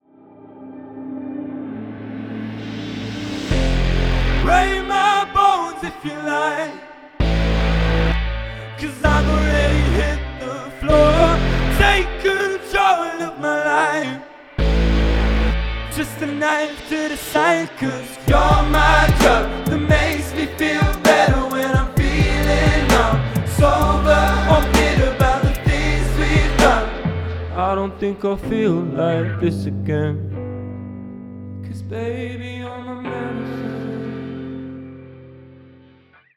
We were lucky to see this local DYNAMIC DUO